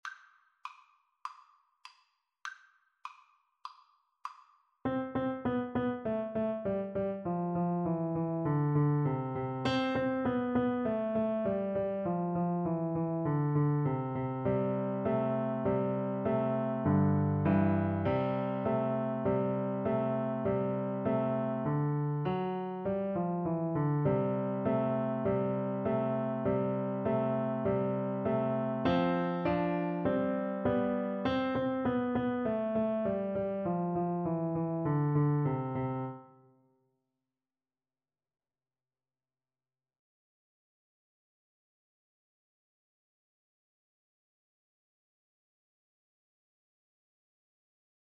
A beginners piece with a rock-like descending bass line.
March-like